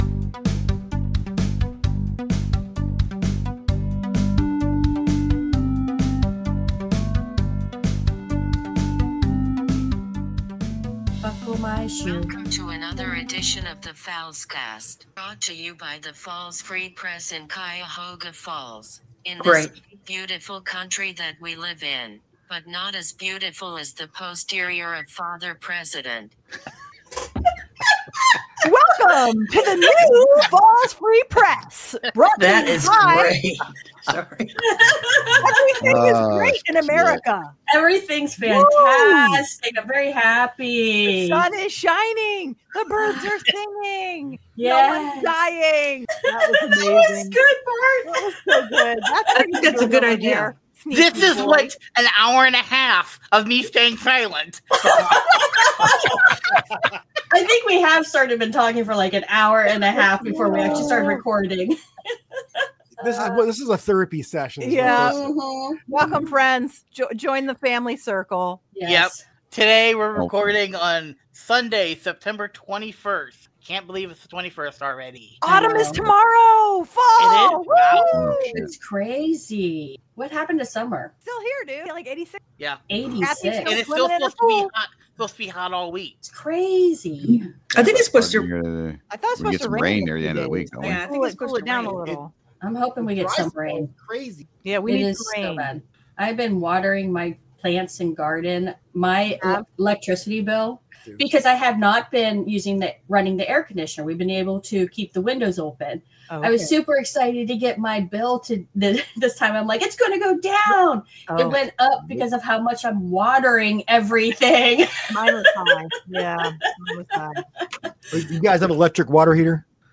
An all-new Fallscast this month with discussion about: